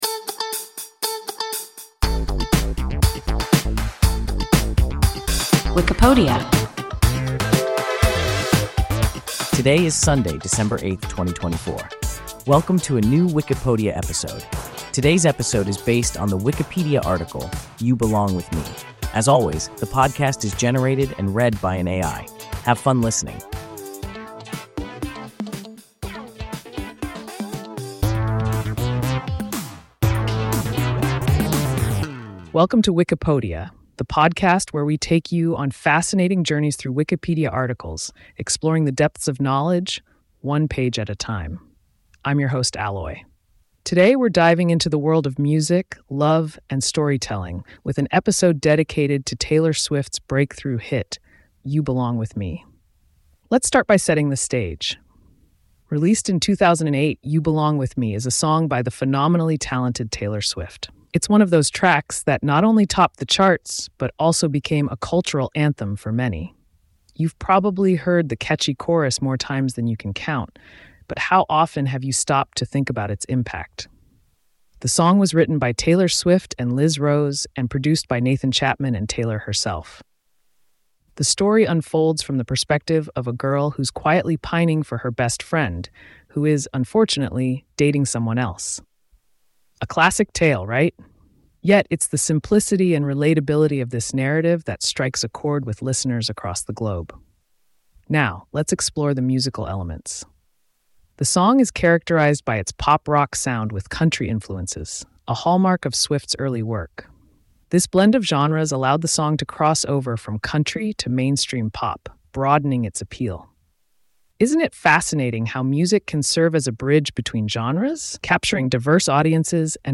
You Belong with Me – WIKIPODIA – ein KI Podcast